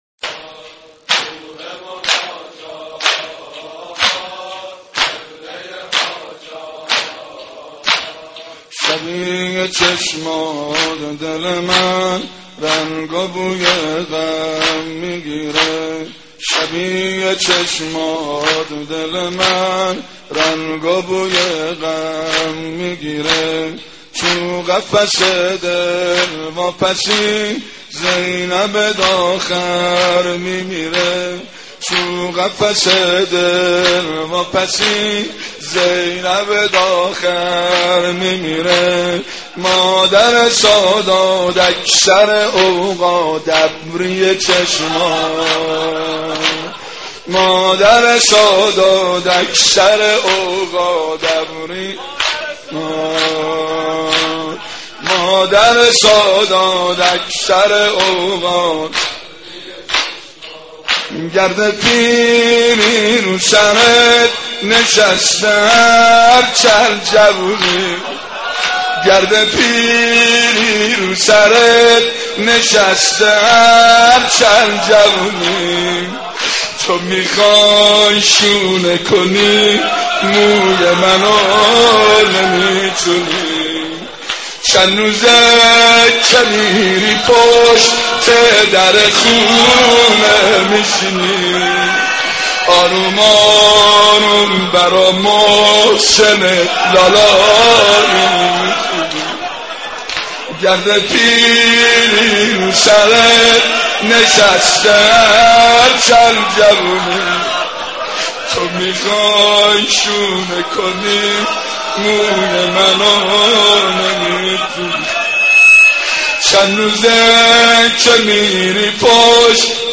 نوحه‌سرایی در سوگ شهادت صدیقه طاهره(ع